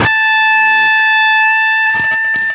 LEAD A 4 CUT.wav